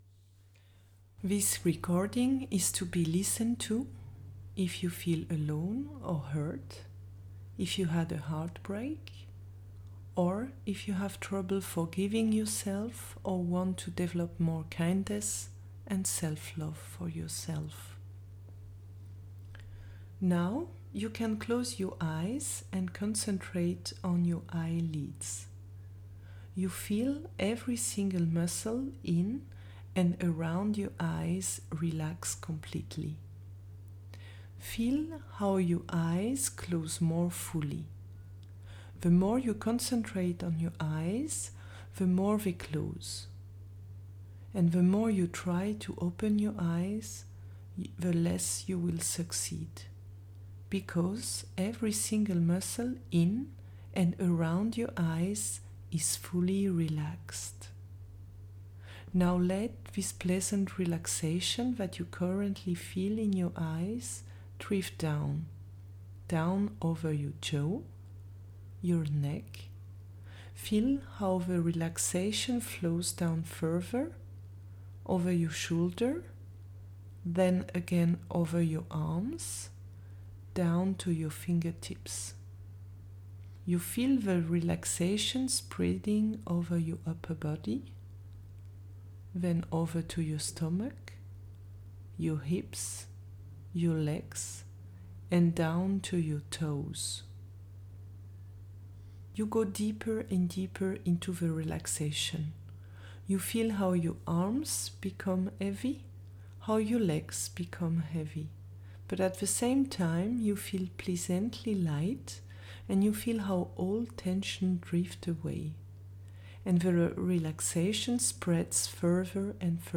For you a meditation to cultivate more self-love DOWNLOAD (without music) Mode of use This recording is to be listened to if you feel alone or hurt, if you lived a heartbreak, if you have trouble forgiving yourself or if you want to develop kindness and selflove for yourself.
selflove-meditation.mp3